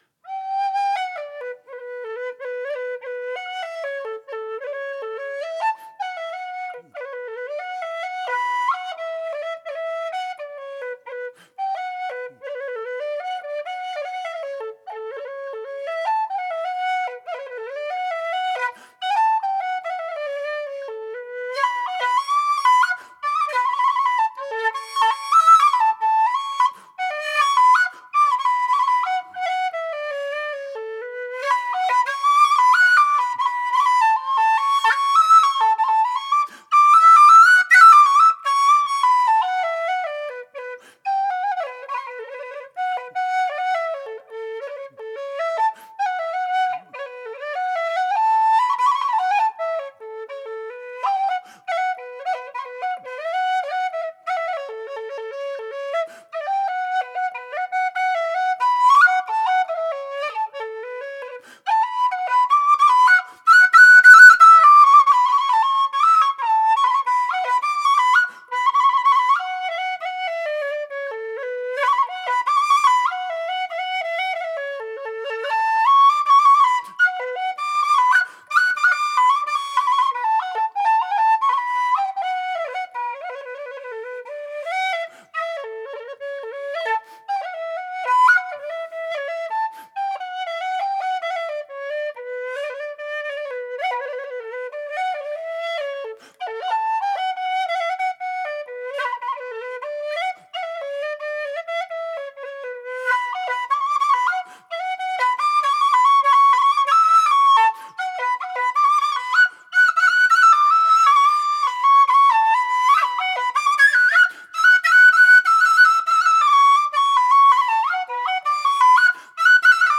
A whistle - 145 GBP
made out of thin-walled aluminium tubing with 15.7mm bore